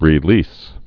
(rē-lēs)